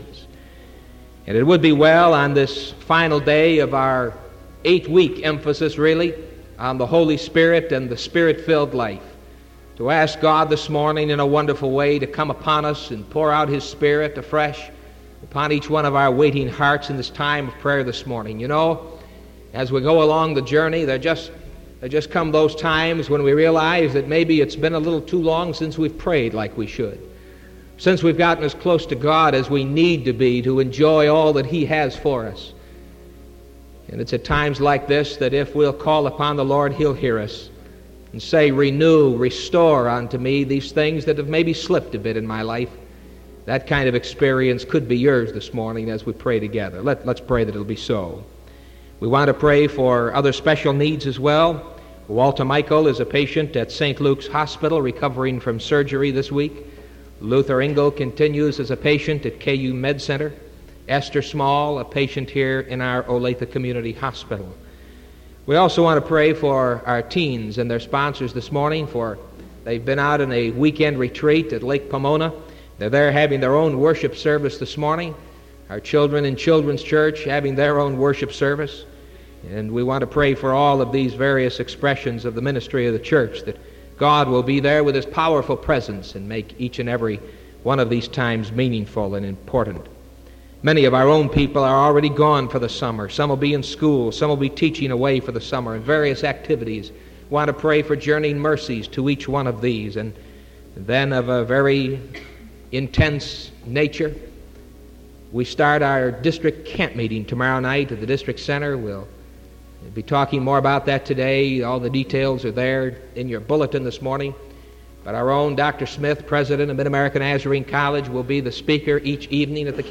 Sermon June 2nd 1974 AM